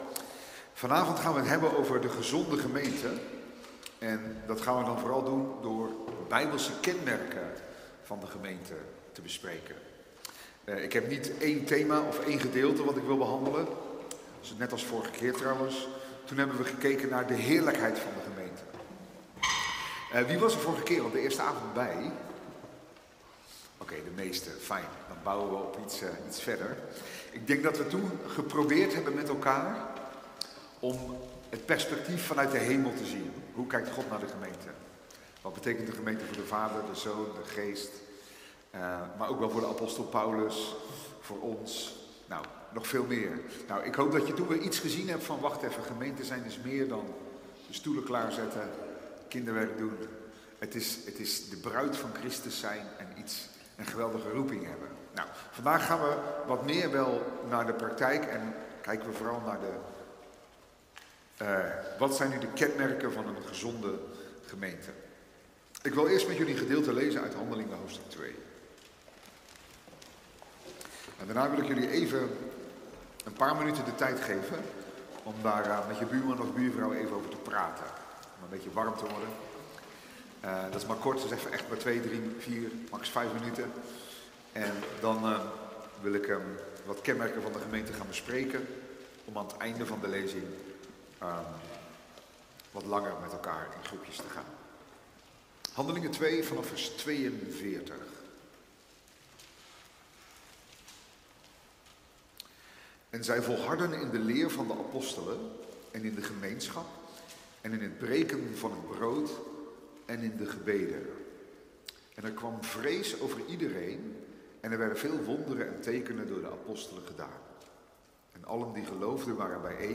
Themastudie